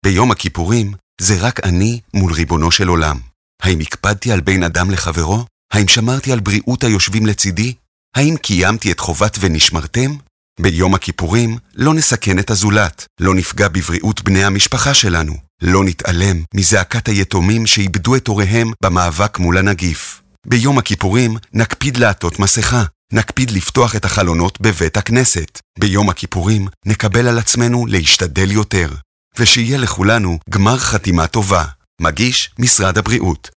במטרה לעודד עטיית מסכות בבתי הכנסת, משגר היום (רביעי) מטה ההסברה החרדי של משרד הבריאות, מאות אלפי הודעות IVR לטלפונים ניידים (כשרים) ונייחים.